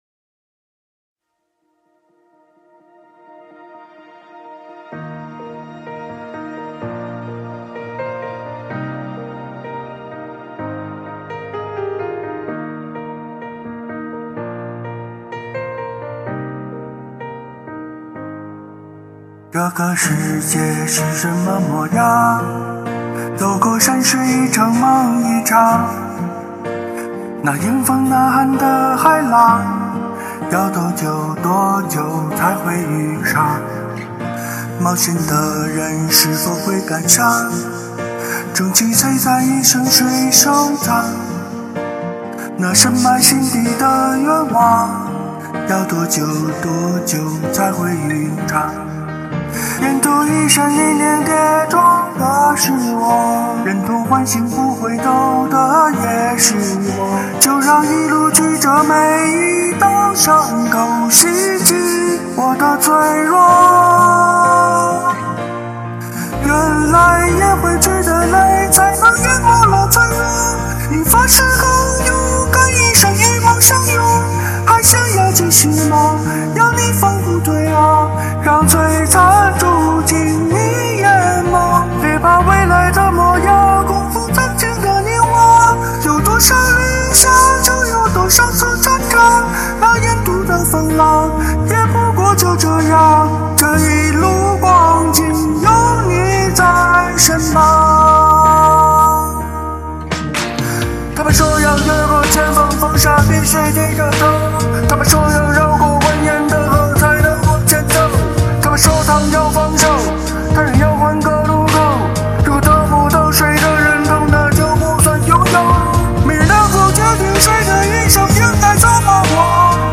[原声翻唱]
深情的演唱非常动听
歌曲第一次听，说唱的形式。调调挺高的。